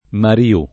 [ mari- 2+ ]